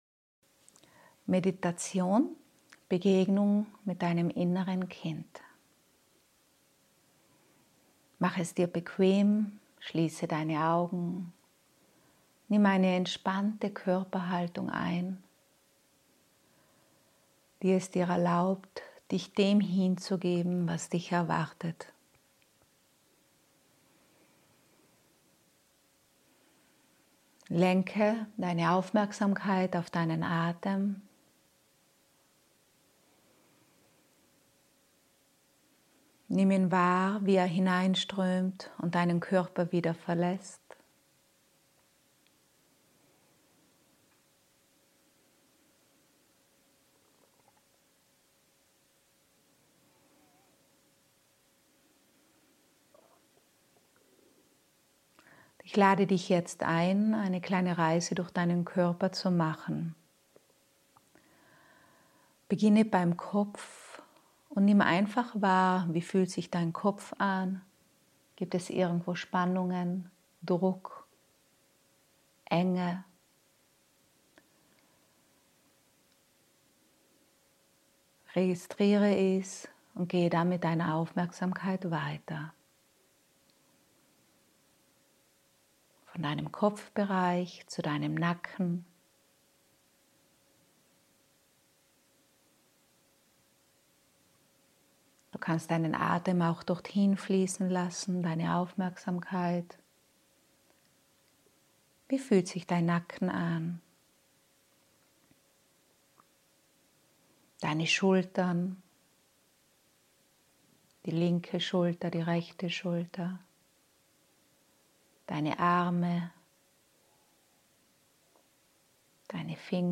Meditation: Begegnung mit dem Inneren Kind